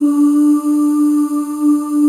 D3 FEM OOS.wav